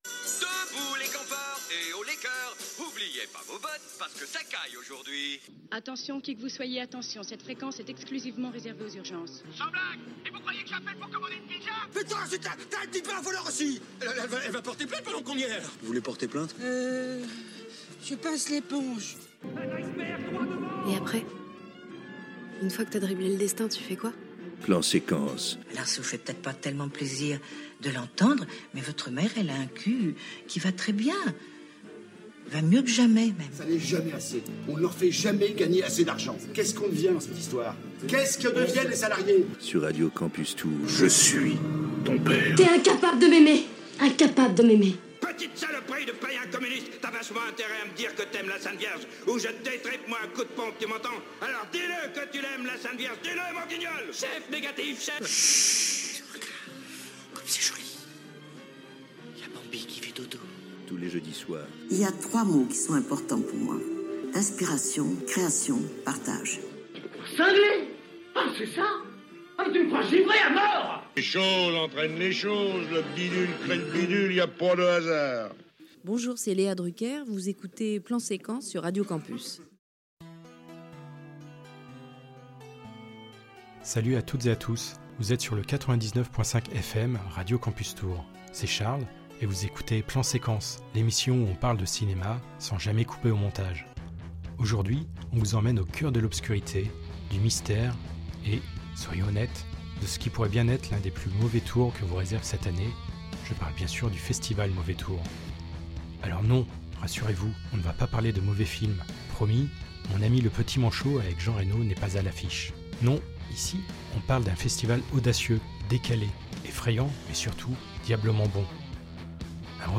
Une deuxième émission en quasi direct de la bibliothèque des Studio